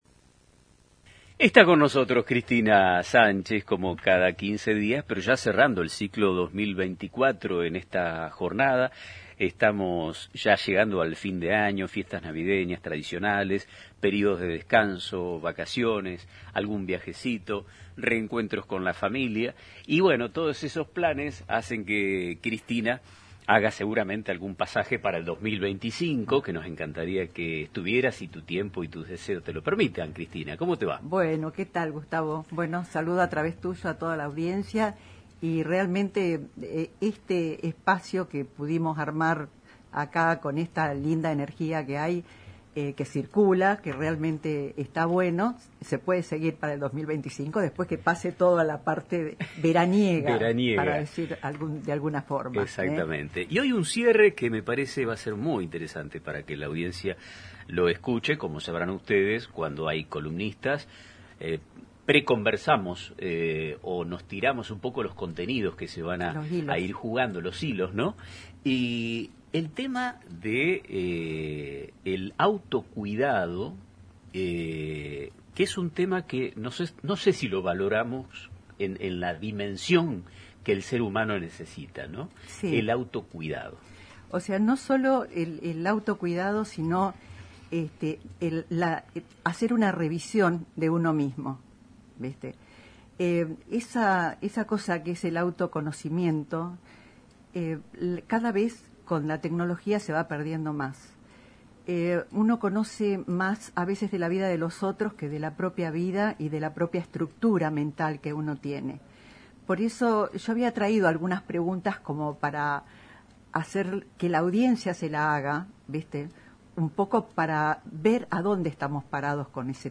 El diálogo inició con un agradecimiento por el espacio y una proyección optimista hacia 2025, destacando la relevancia de generar instancias de reflexión en una sociedad cada vez más desconectada de su interioridad.